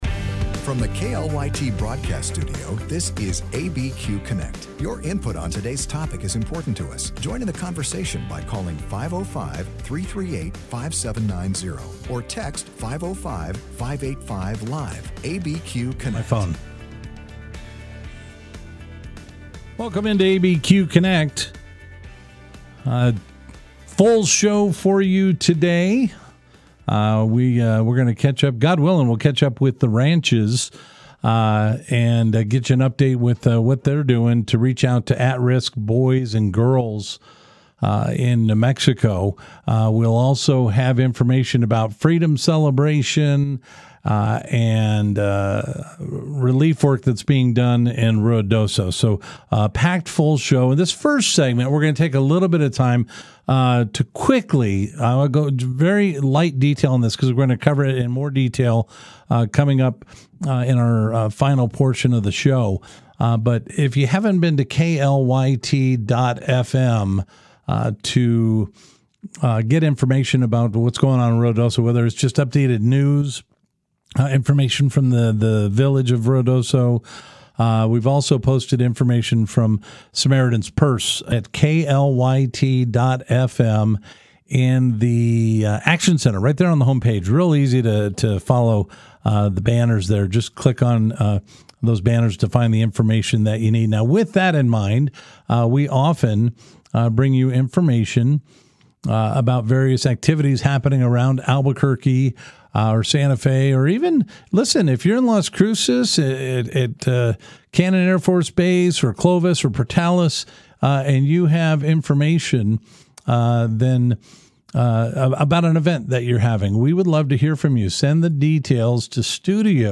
Live, local and focused on issues that affect those in the New Mexico area.